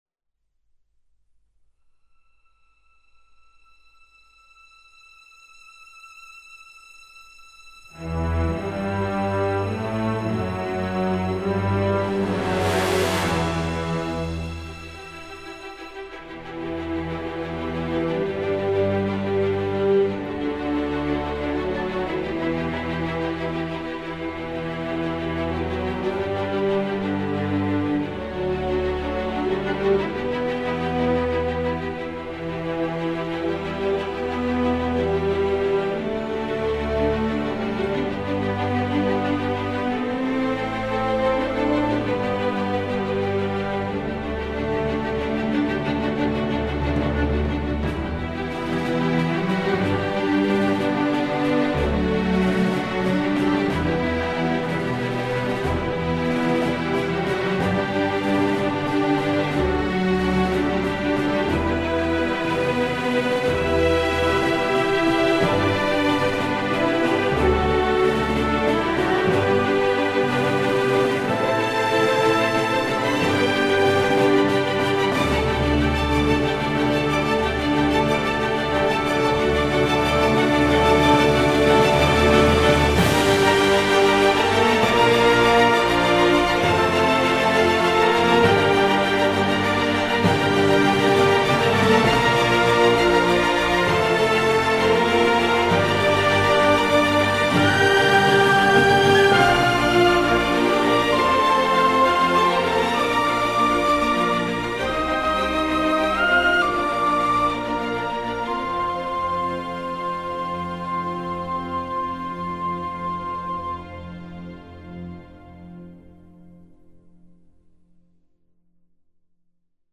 ساندترک موسیقی بیکلام